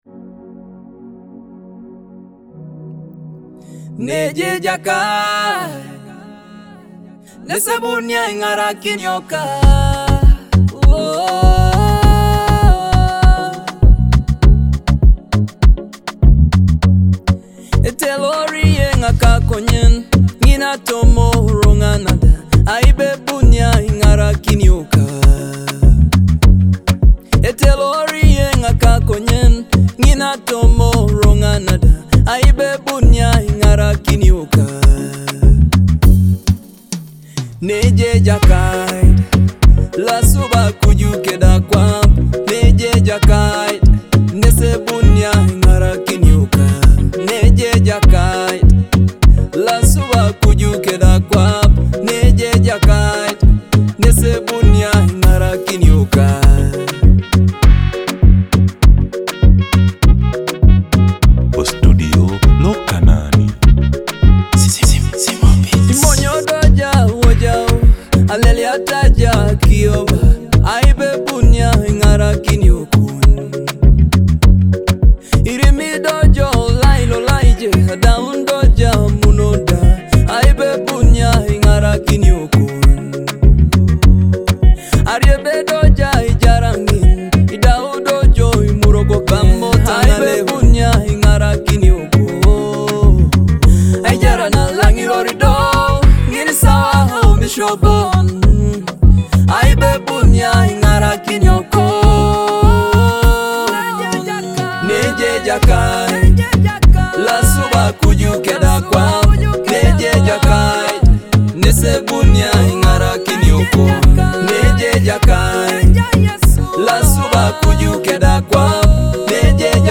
an uplifting Teso gospel song inspired by Psalm 121.
a soul-stirring gospel song
powerful vocals, and a blend of traditional gospel melodies